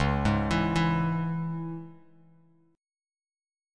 Listen to C#m strummed